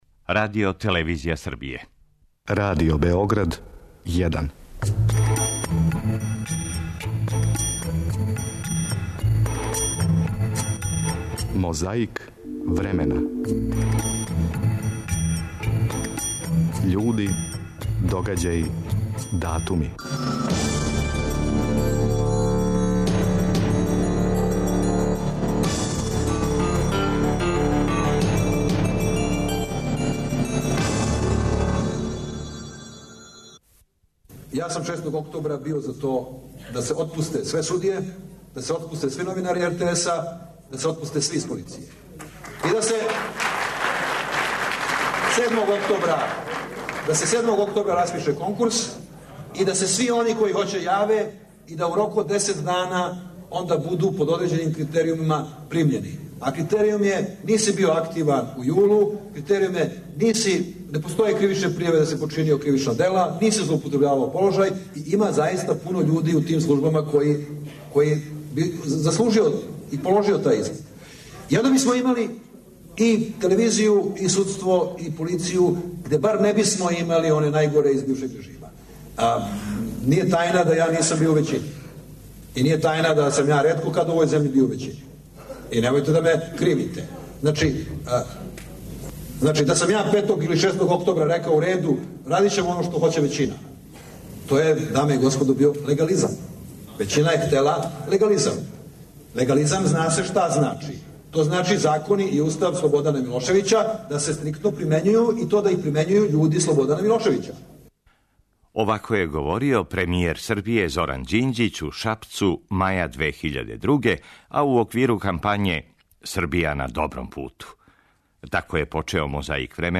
Слушамо архивске снимке Радио Београда као и друге врсте звучног архива било ког порекла везане за дате догађаје.